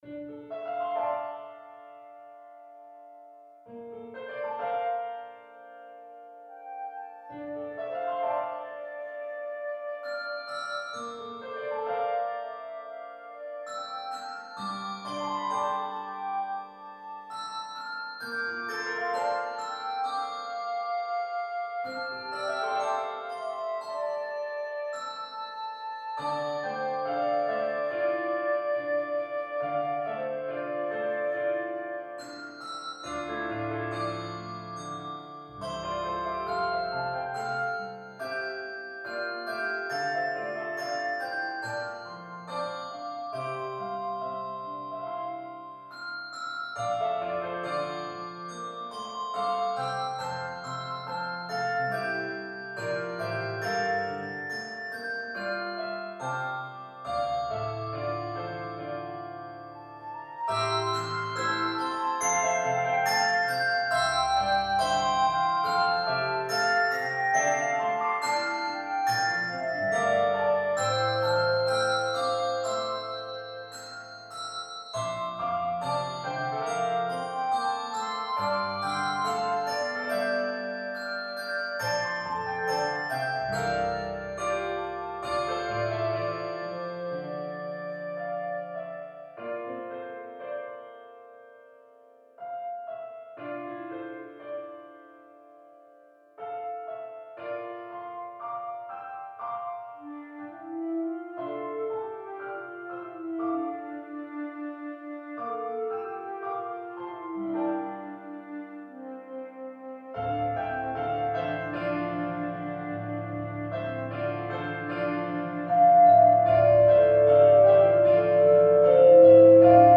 Key of d minor.